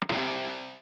snd_notespawn.ogg